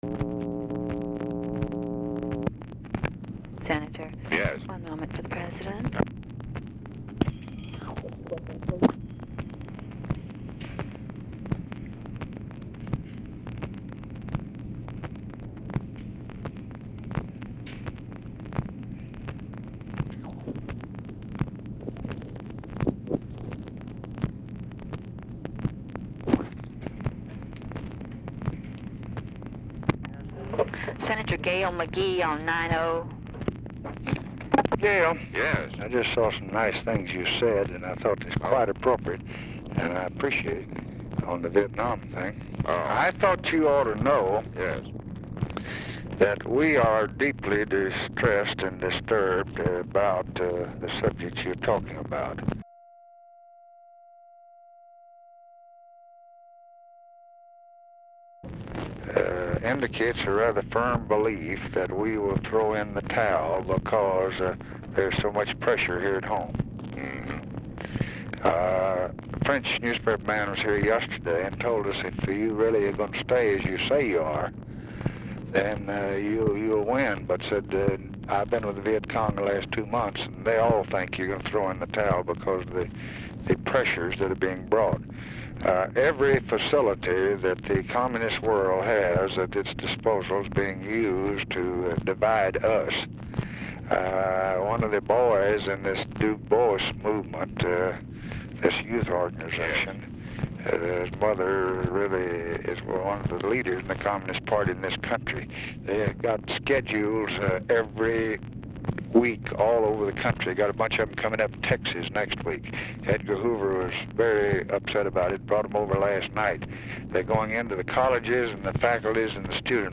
Telephone conversation # 7384, sound recording, LBJ and GALE MCGEE, 4/29/1965, 4:29PM | Discover LBJ
Format Dictation belt
Location Of Speaker 1 Oval Office or unknown location
Specific Item Type Telephone conversation